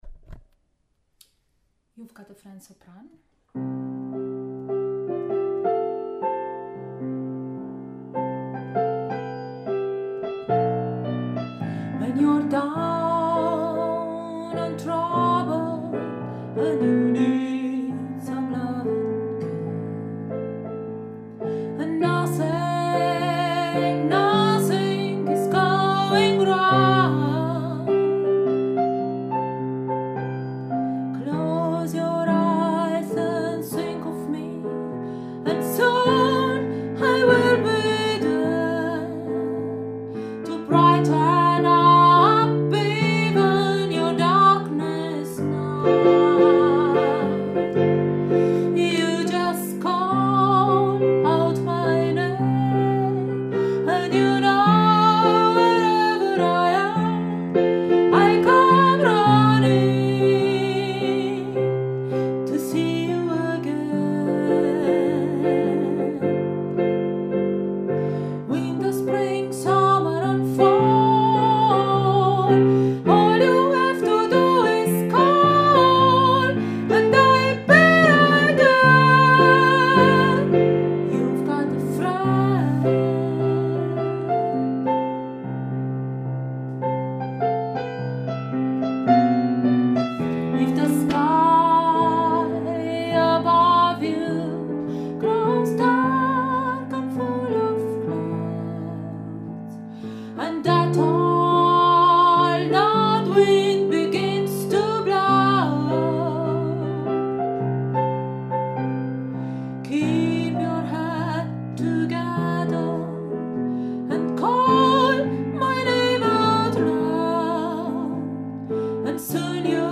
You’ve got a friend – Sopran